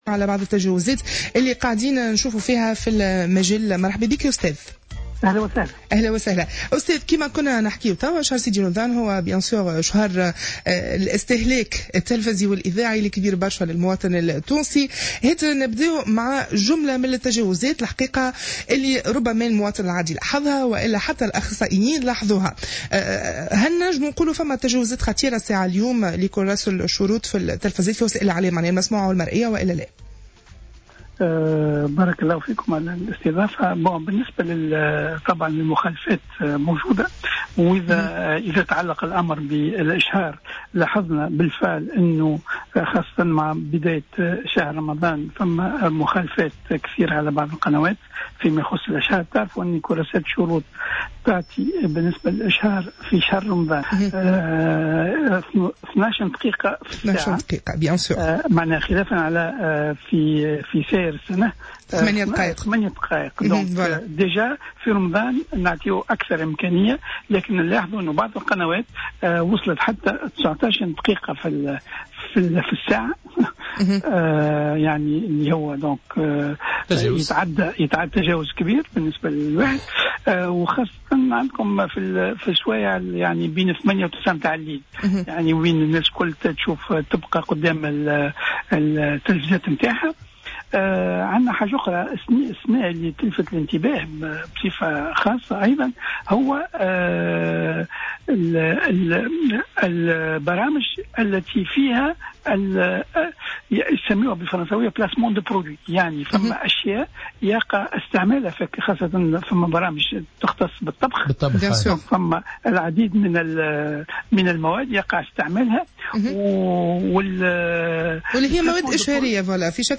أكد رئيس الهيئة العليا المستقلة للإتصال السمعي البصري النوري اللجمي في تصريح لجوهرة "اف ام" اليوم ان عدد من القنوات التلفزية ترتكب مخالفات عديدة خاصة في ما يتعلق بالمدة المسموح بها للإشهار والمحددة في شهر رمضان ب12 دقيقة مشيرا إلى أن هناك قنوات وصلت مدة بث اشهارها الى 19 دقيقة.